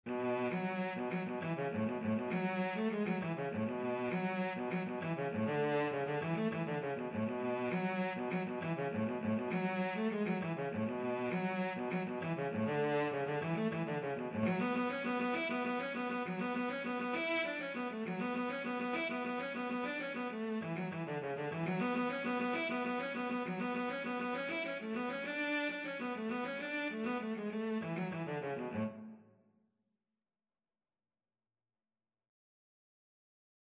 6/8 (View more 6/8 Music)
Cello  (View more Intermediate Cello Music)
Traditional (View more Traditional Cello Music)